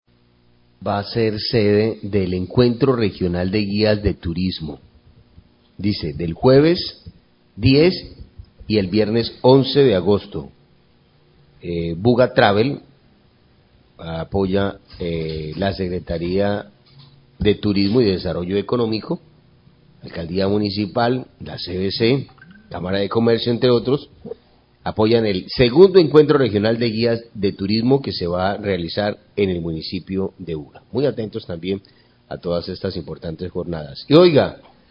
Radio
El Secretario de Turismo y Desarrollo Económico de Buga, Luis Eduardo Bravo, habla de los preparativos del Buga Encuentro Regional de Guías de Turismo que, en esta versión, también tendrá participación de invitados de otras regiones del país.